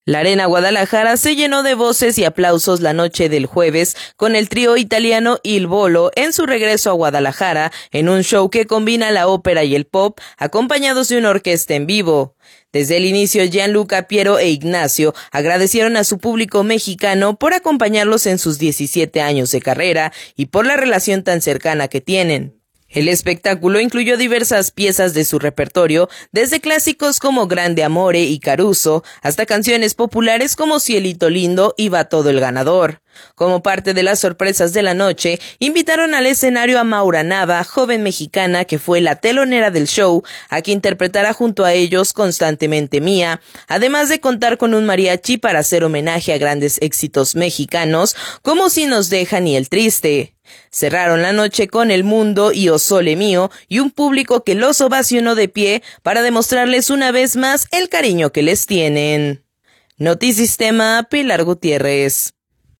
La Arena Guadalajara se llenó de voces y aplausos la noche del jueves con el trío italiano Il Volo en su regresó Guadalajara, en un show, que combina la ópera y el pop, acompañados de una orquesta en vivo.